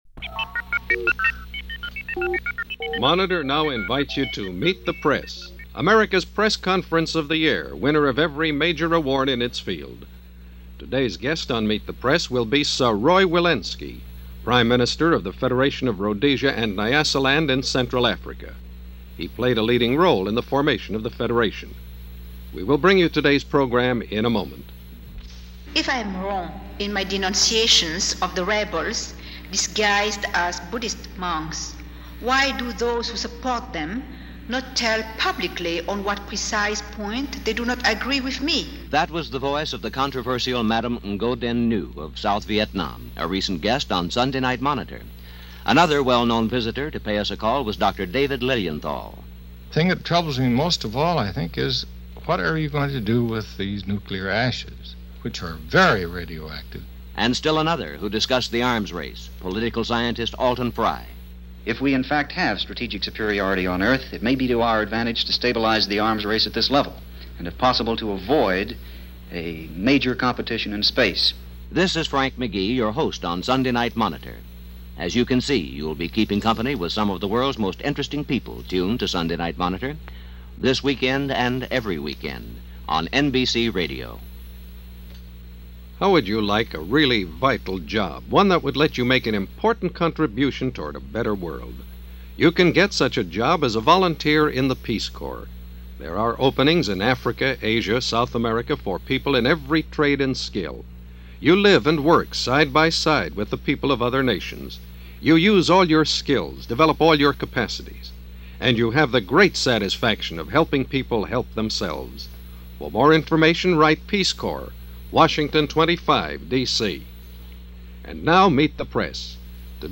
Africa In Transition - Interview With Sir Roy Walensky Of Rhodesia - 1963 - Past Daily Reference Room
Here is that complete broadcast interview with Sir Roy Walensky of Southern Rhodesia from Meet The Press for October 27, 1963.